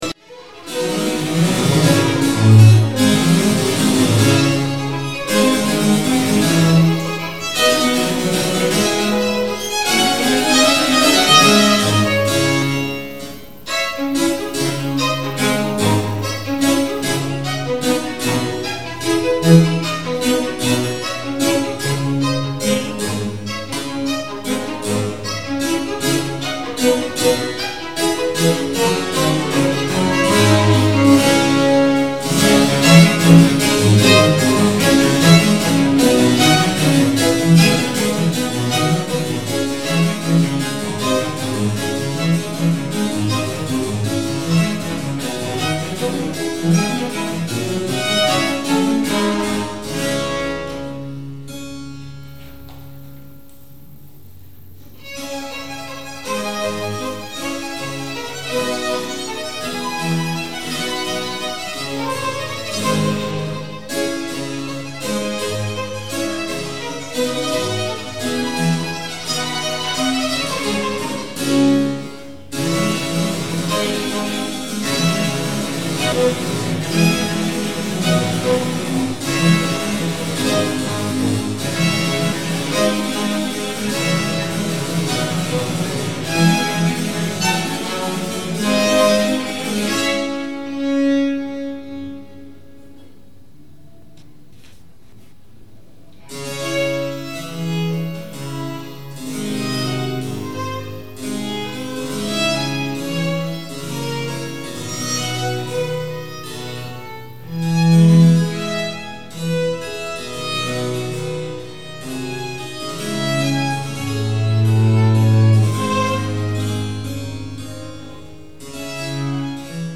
Percorsi della sonata solistica tra sei e settecento images taken from the live web broadcast
Violino
Violoncello
Clavicembalo
barga cordati concert.mp3